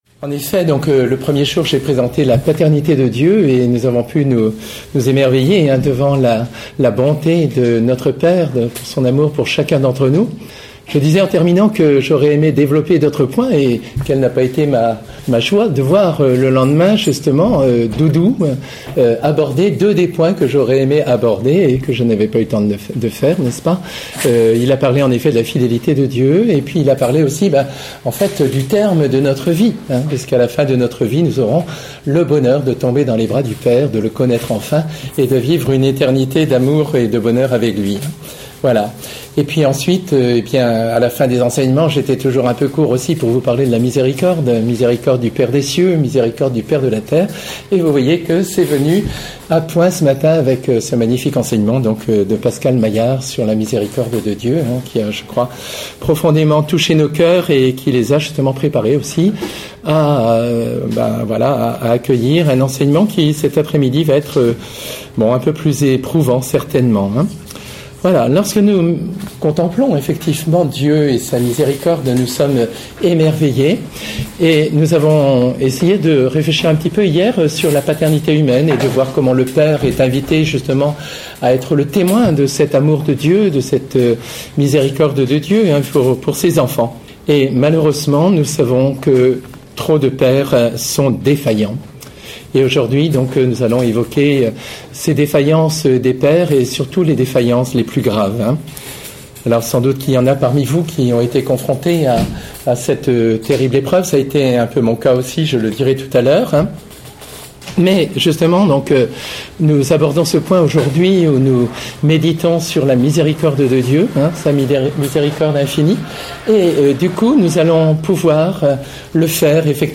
Enseignement
Enregistré à la session des Béatitudes Lisieux 2014
Format :MP3 64Kbps Mono